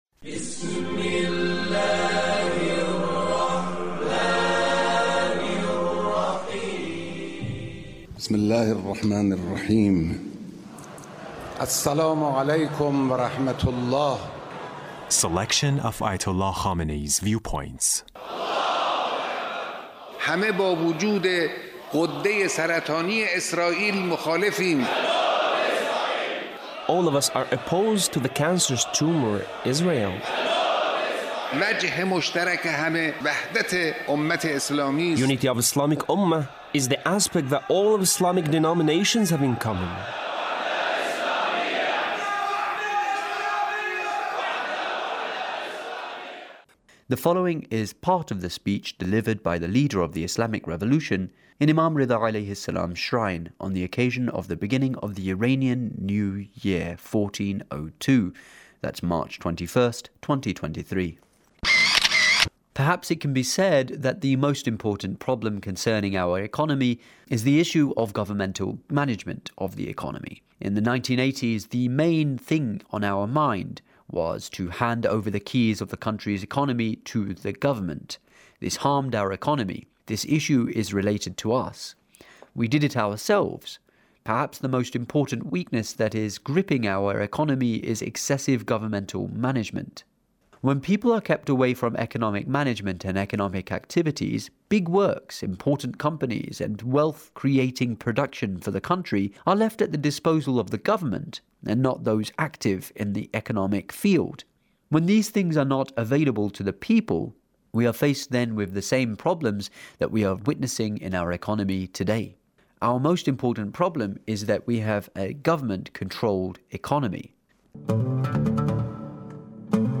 Leader's Speech on Norooz 1402